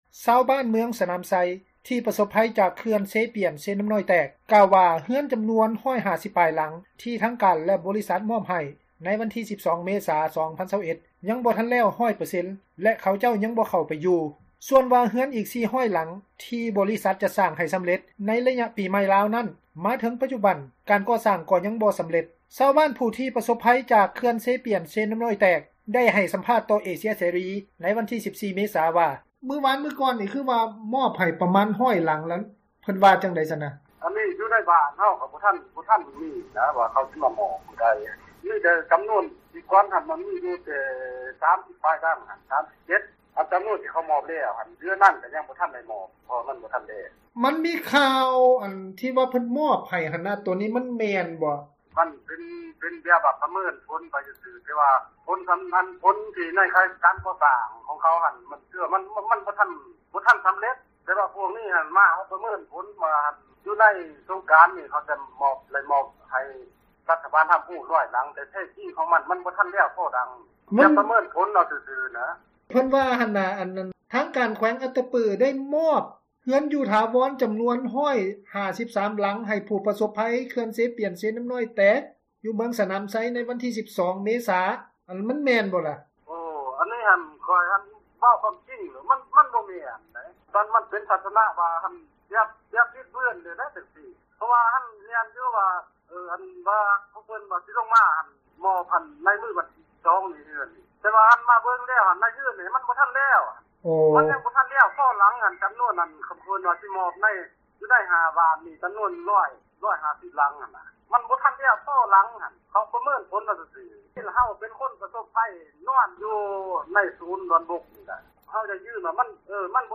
ຊາວບ້ານຜູ້ທີ່ປະສົບພັຍ ຈາກ ເຂື່ອນເຊປຽນ-ເຊນ້ຳນ້ອຍແຕກ ໄດ້ໃຫ້ສຳພາດ ຕໍ່ເອເຊັຽເສຣີ ໃນວັນທີ 14 ເມສາ ວ່າ: